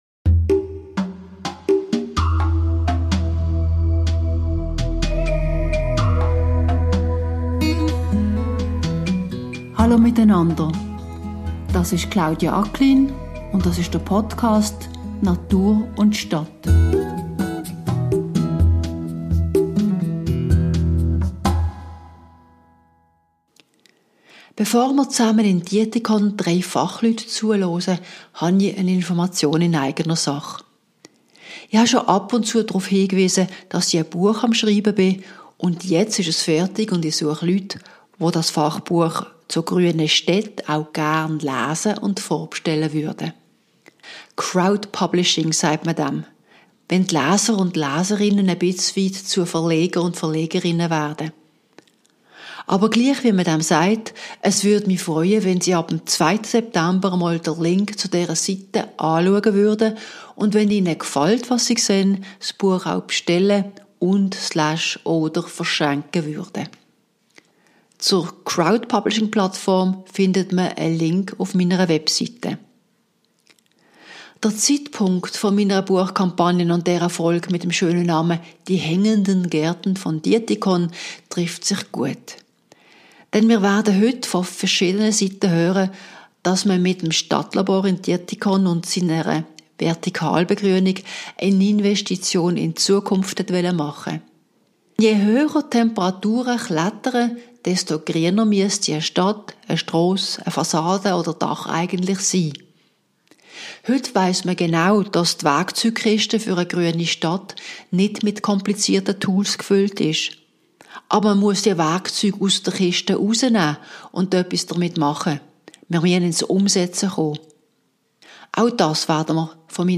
Es war 33 Grad heiss, als wir uns für diese Episode von "Die Natur und die Stadt" im Limmattal trafen.